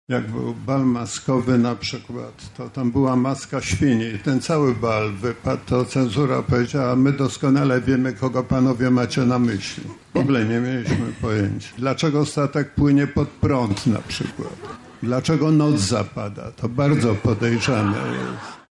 Janusz Głowacki spotkał się z widzami podczas przedostatniego dnia festiwalu filmu i sztuki Dwa Brzegi w Kazimierzu Dolnym. Pisarz opowiadał o tworzeniu scenariusza do filmu „Wałęsa. Człowiek z nadziei”, nie zabrakło też wspomnień z przeszłości.